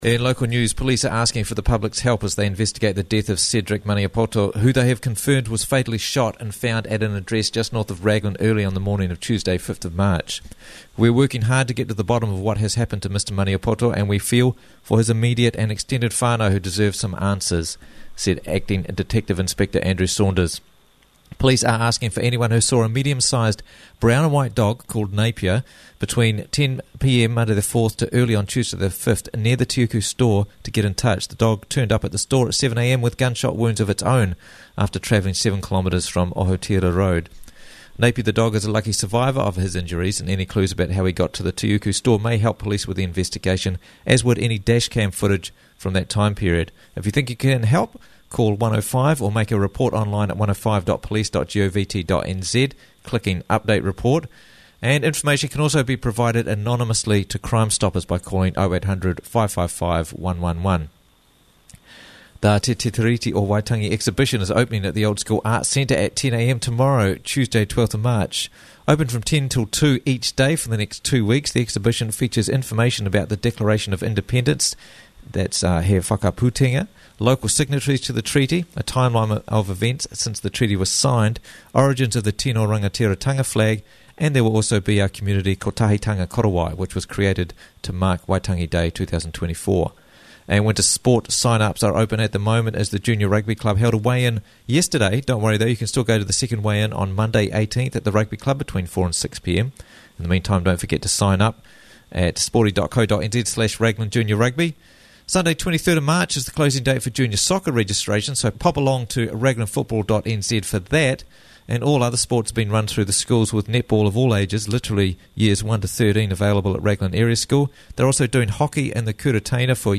Raglan News 11th March 2024 - Raglan News Bulletin